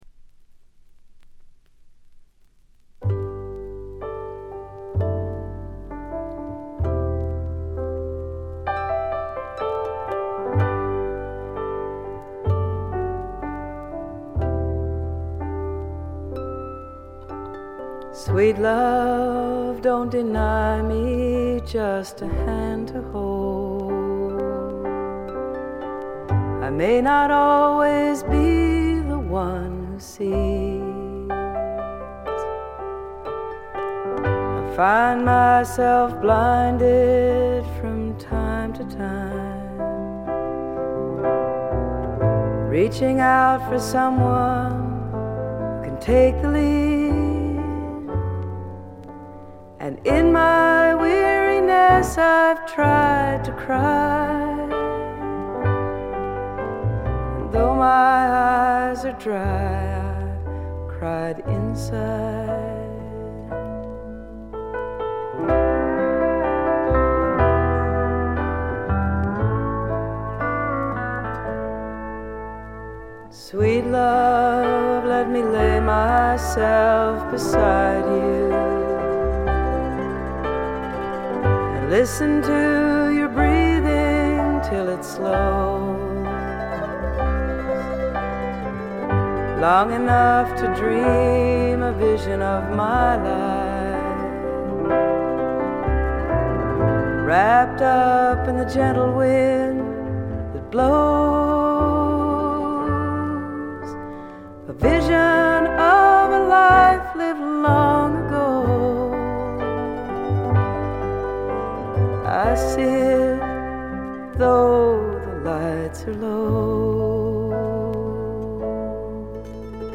しみじみとした歌の数々が胸を打つ女性フォーキー・シンガーソングライターの基本です。
試聴曲は現品からの取り込み音源です。
vocals, guitar, piano